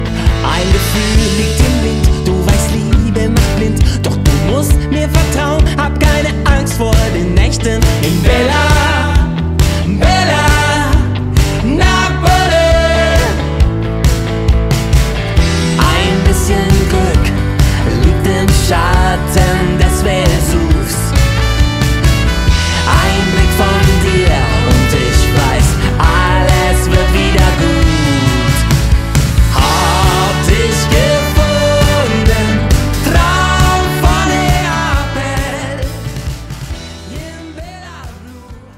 Gattung: Moderner Einzeltitel
Besetzung: Blasorchester
Tonart: Bb-Dur